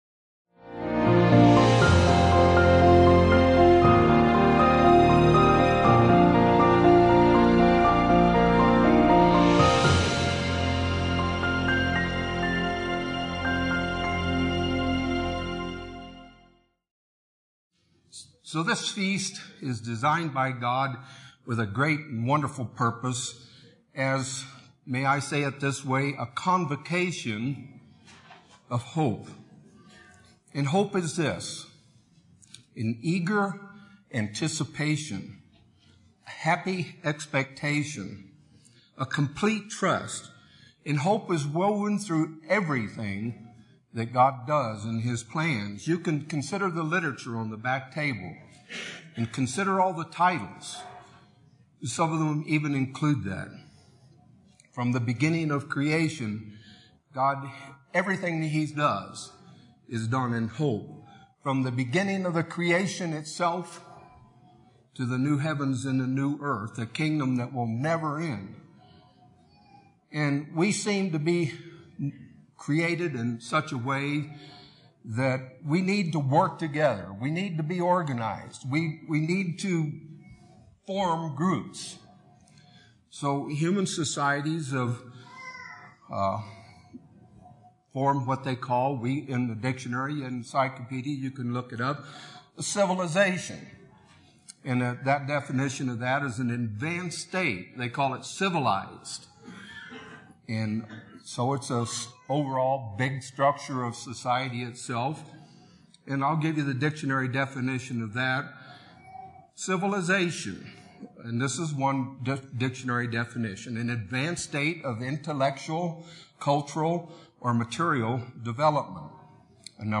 This sermon was given at the Steamboat Springs, Colorado 2016 Feast site.